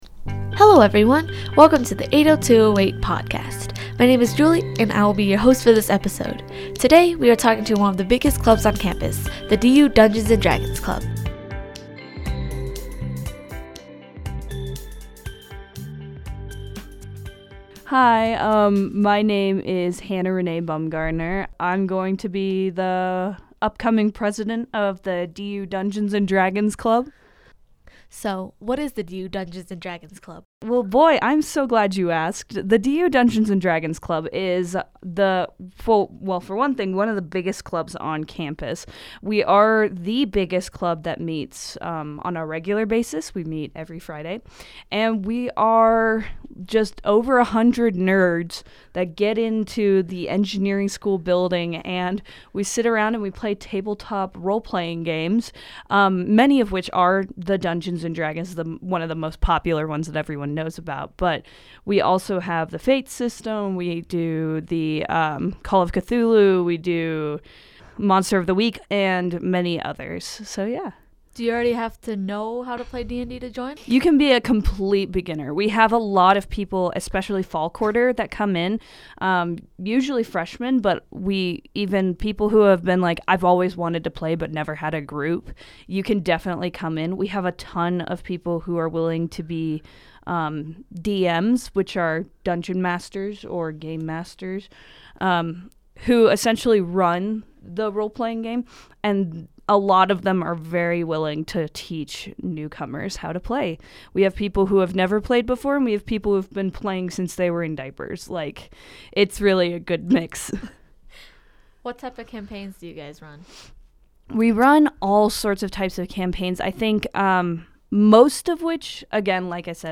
80208 Background music